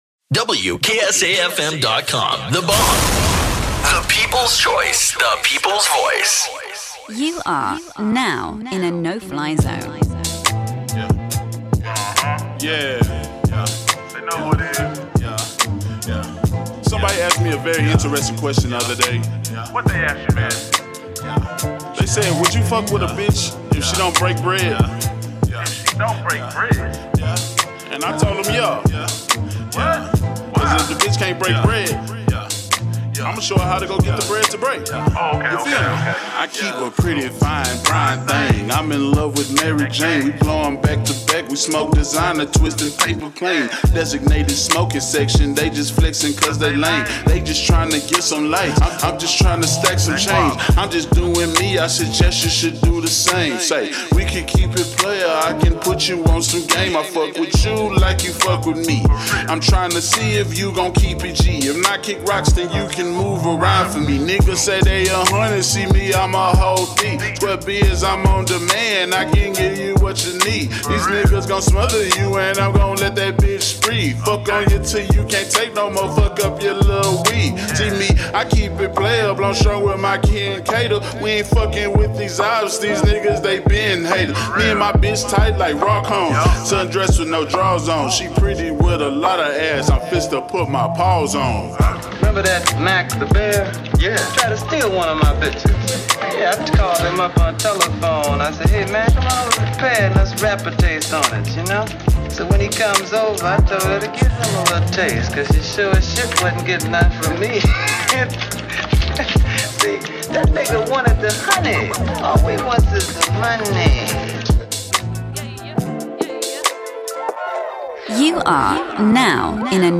DJ Mixes
HIP HOP